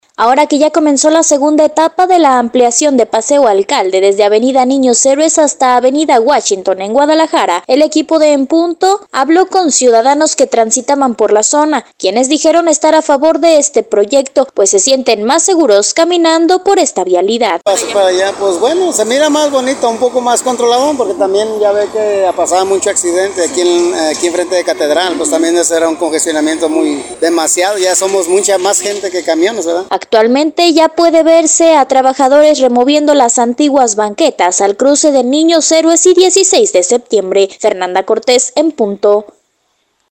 Ahora que ya comenzó la segunda etapa de la ampliación de paseo alcalde, desde avenida Niños Héroes hasta avenida Washington, el equipo de En Punto hablo con ciudadanos que transitadan por la zona quienes dijeron estar a favor de este proyecto, pues sienten estar más seguros caminando por esta vialidad.